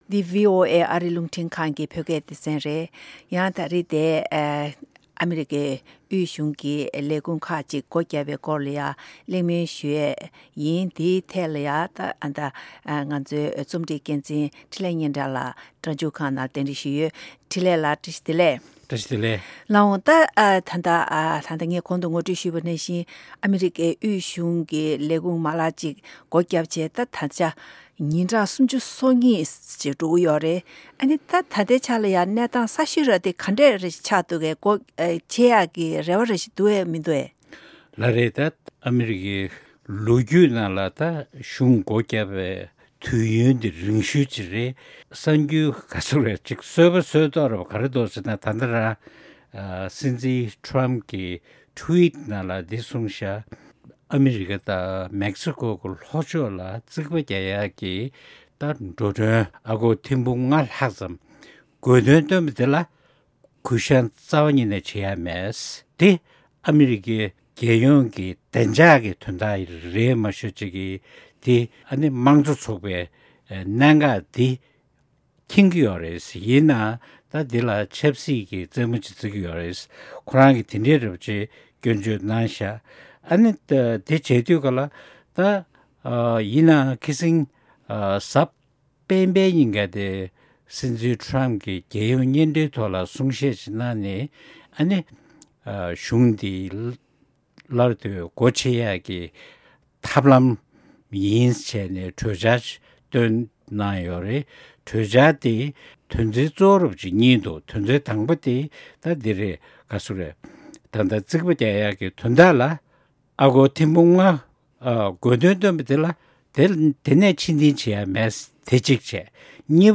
བཅར་འདྲི་འདྲི་ཞུས་པ་ཞིག་གསན་རོགས་གནང་།།